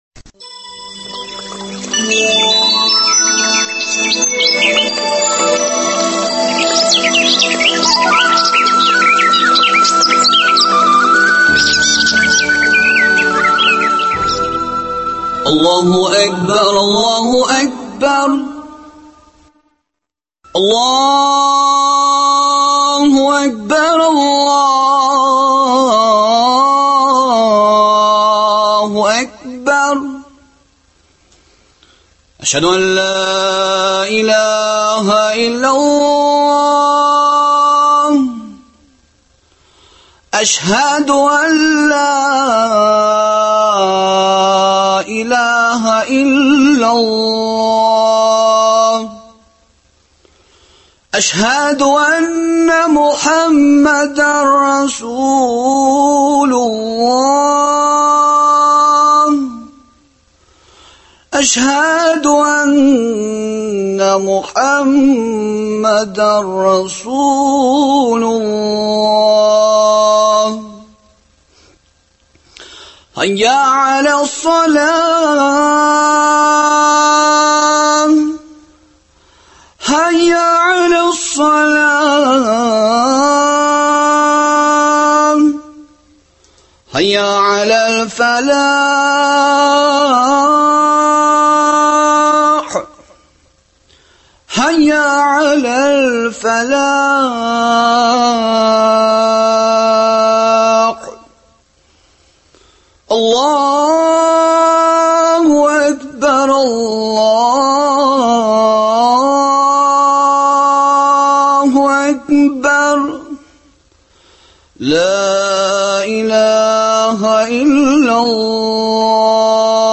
шушы темага әңгәмә кордык